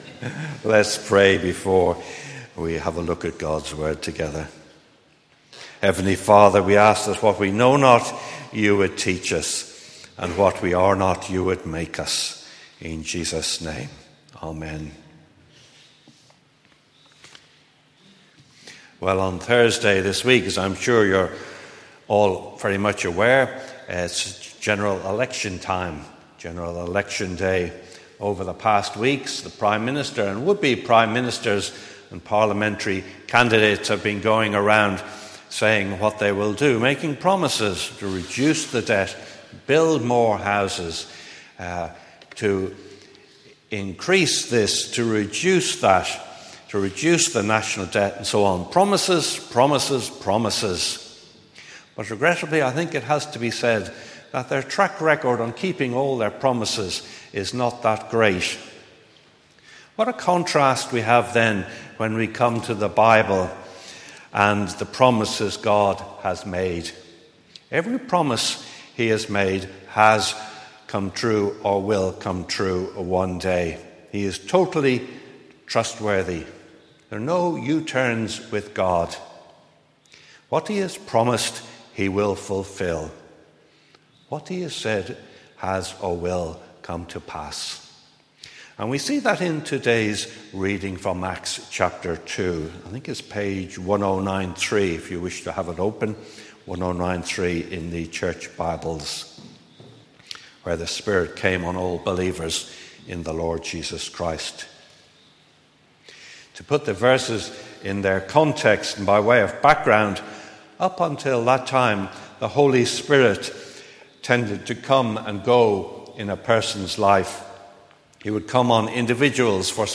Today’s sermon covers the theme of Pentecost.